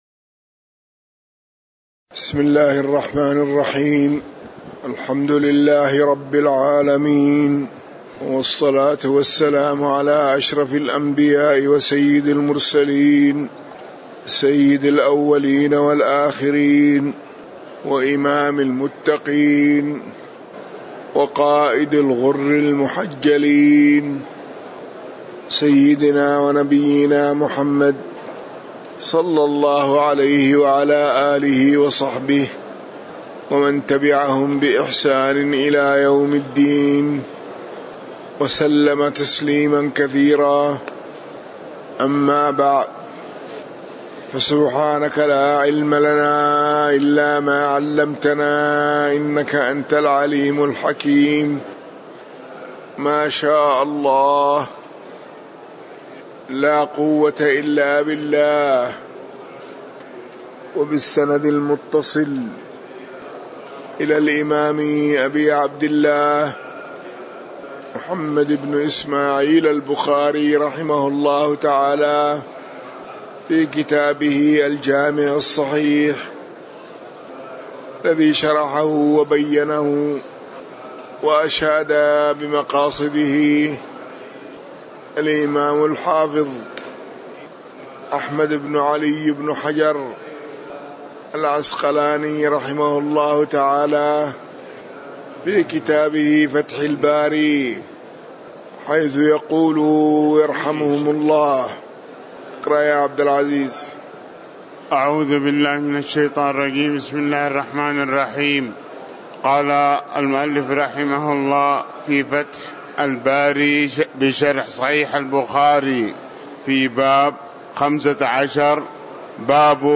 تاريخ النشر ٧ جمادى الآخرة ١٤٤٠ هـ المكان: المسجد النبوي الشيخ